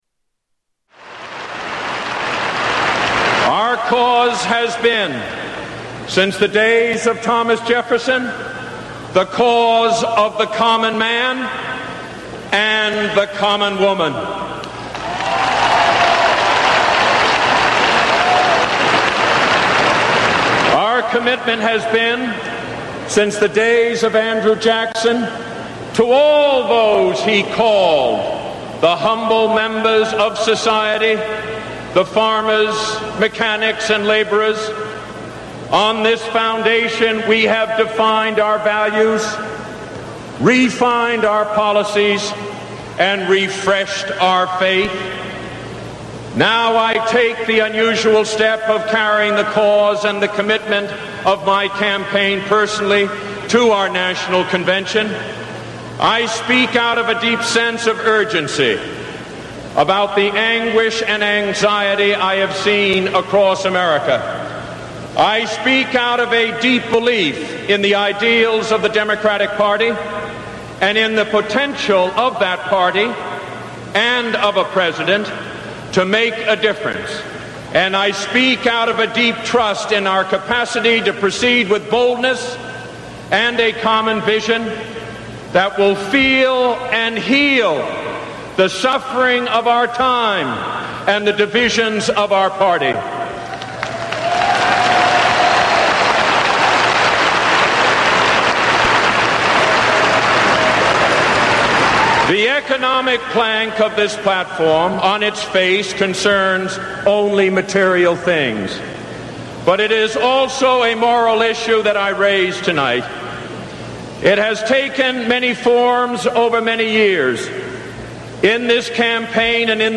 1980 Democratic Convention prt 2